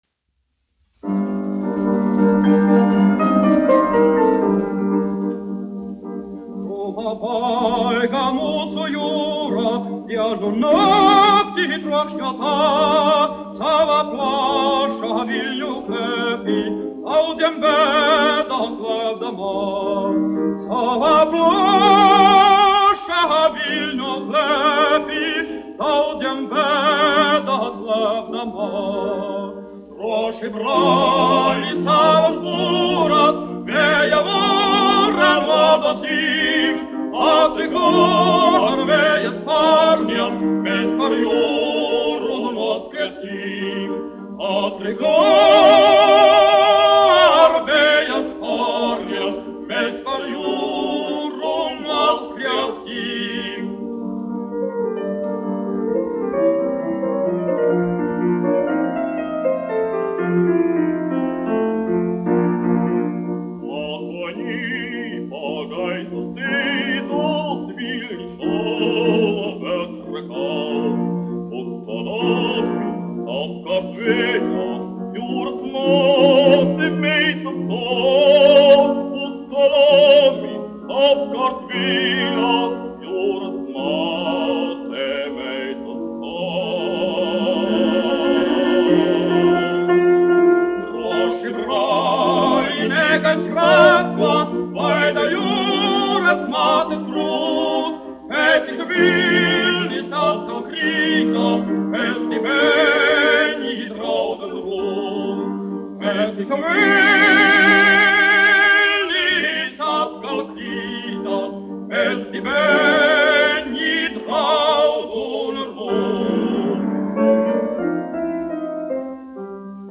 1 skpl. : analogs, 78 apgr/min, mono ; 25 cm
Vokālie dueti ar klavierēm
Skaņuplate
Latvijas vēsturiskie šellaka skaņuplašu ieraksti (Kolekcija)